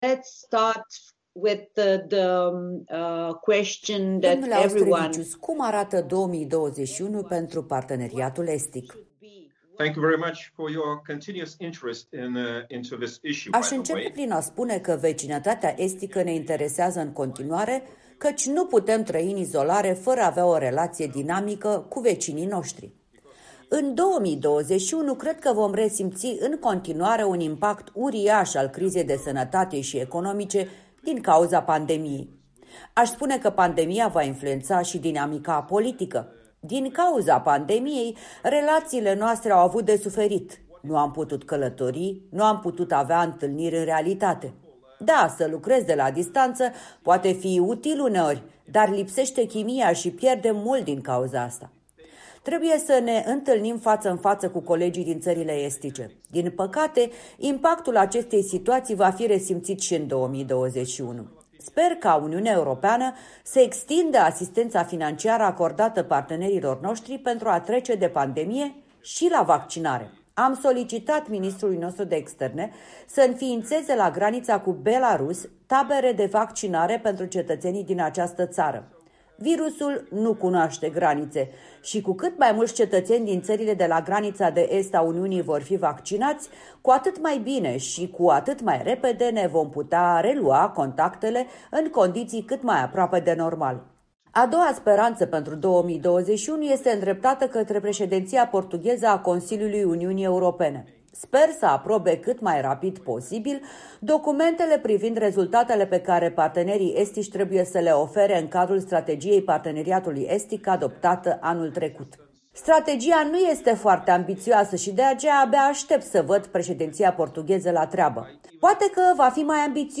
Interviu in exclusivitate cu europarlamentarul lituanian Petras Auštrevičius (Renew Europe)